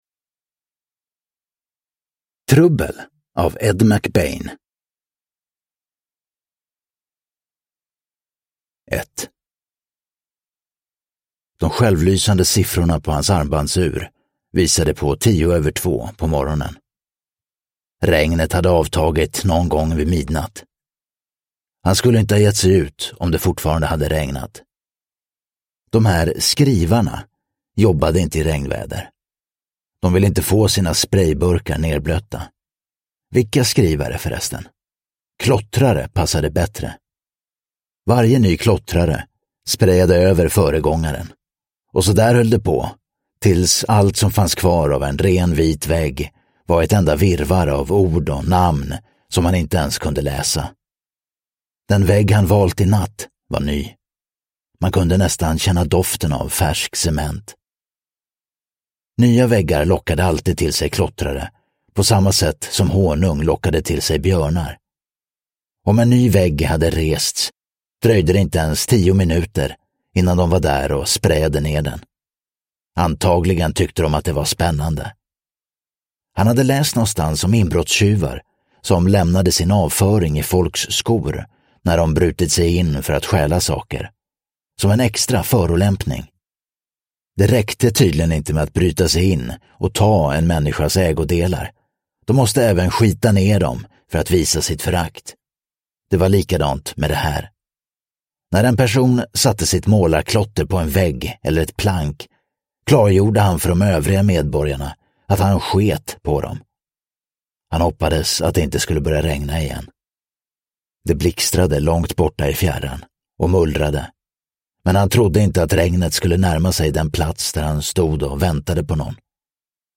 Trubbel – Ljudbok – Laddas ner